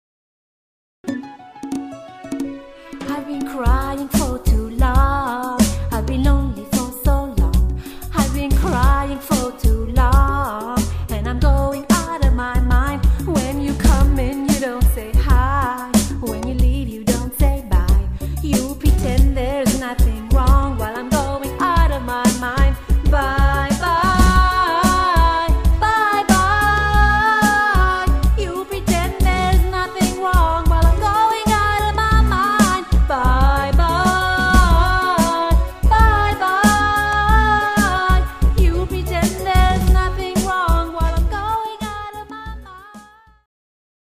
Category: Pop